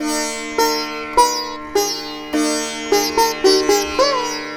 105-SITAR5-L.wav